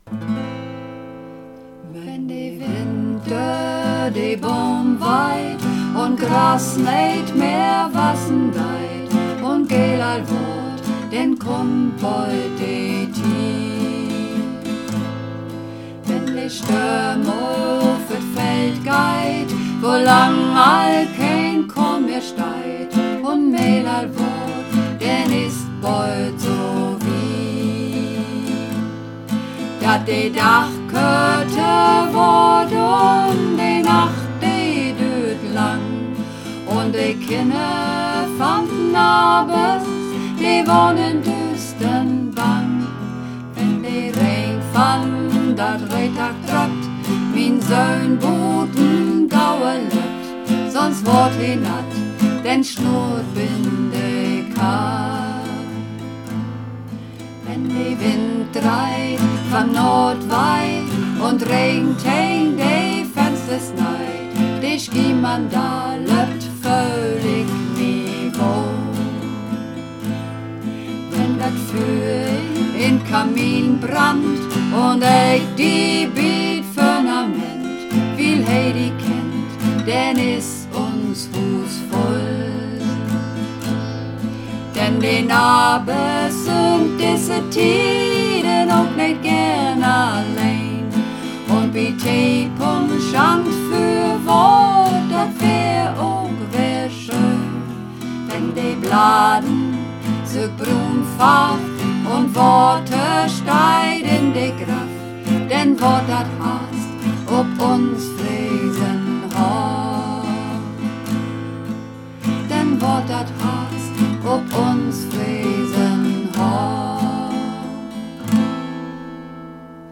Übungsaufnahmen - Fresenhof
Fresenhof (Mehrstimmig)
Fresenhof__3_Mehrstimmig.mp3